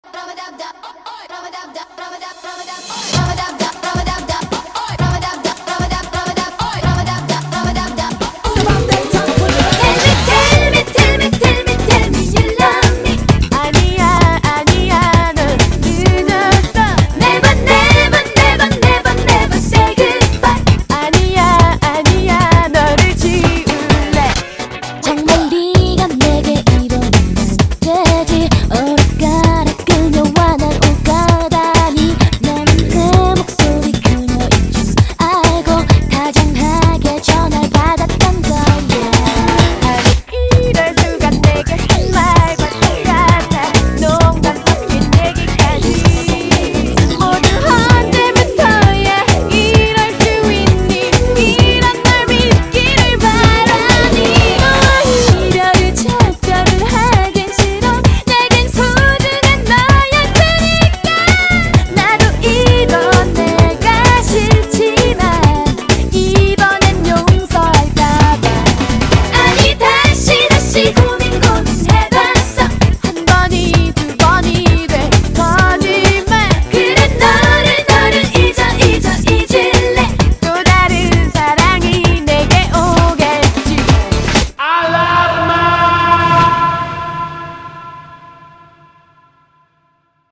BPM130--1
Audio QualityPerfect (High Quality)